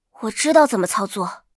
尘白禁区_安卡希雅语音_交互.mp3